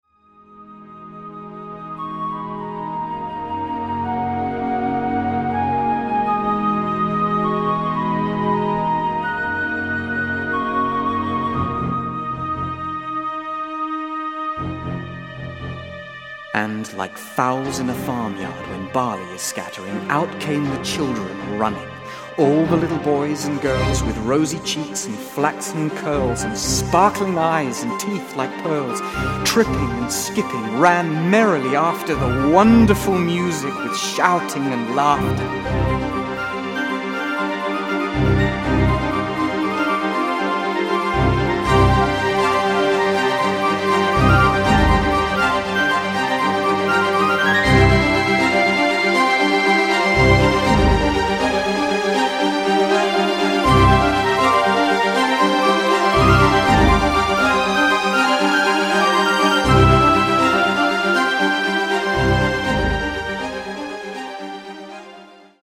Concerto for Flute,
String Orchestra and
Narrator (30 mins)